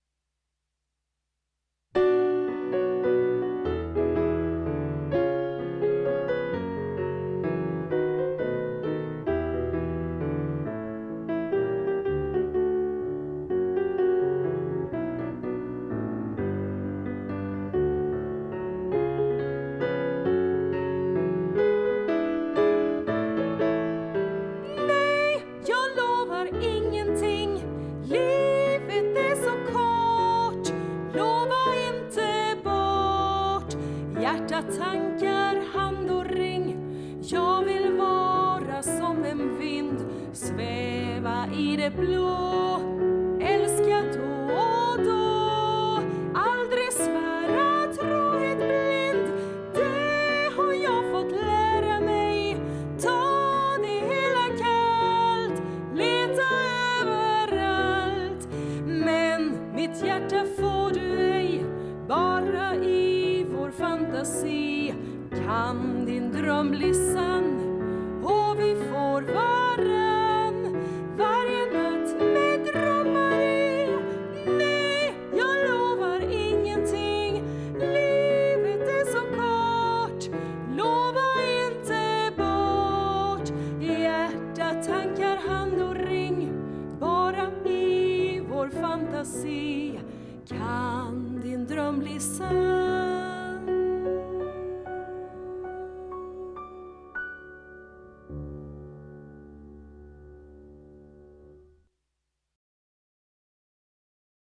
Musik och arrangemang och piano